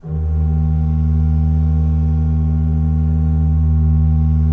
Index of /90_sSampleCDs/Propeller Island - Cathedral Organ/Partition K/ROHRFLOETE R